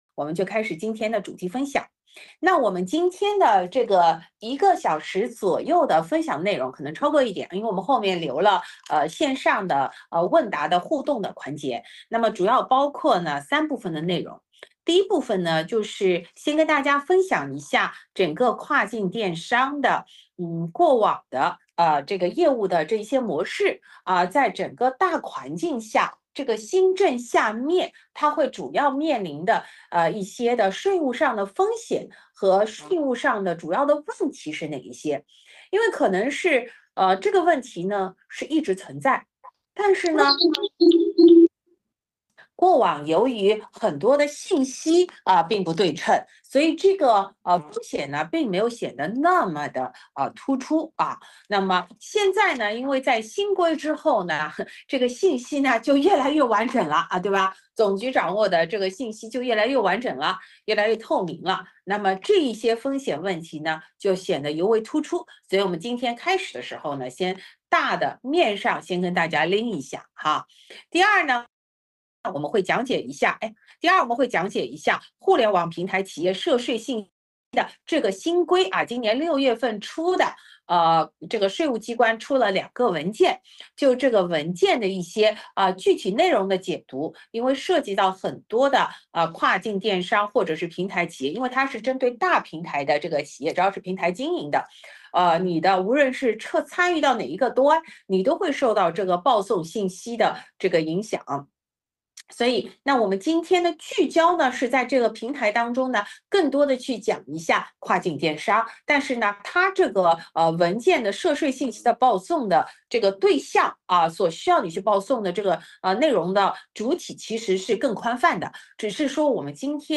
视频会议